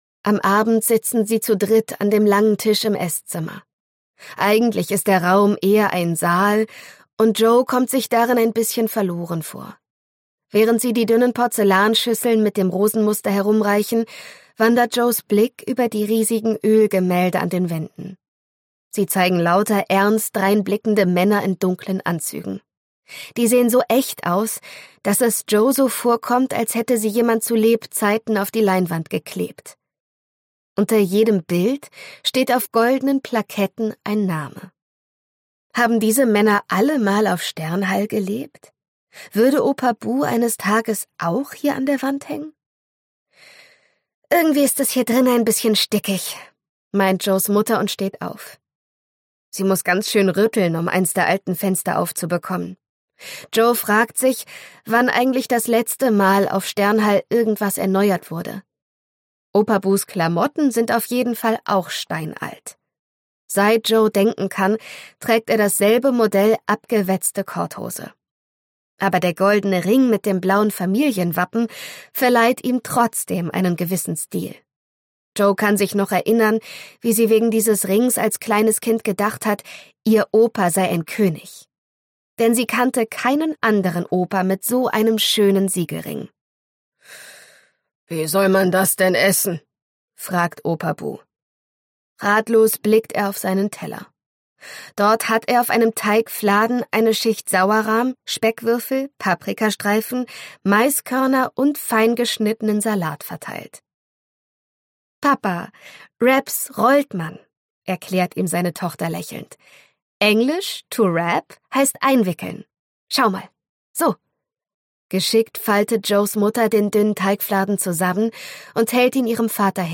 Hörbuch: Die Flügelpferde von Sternhall (1).
Ungekürzte Lesung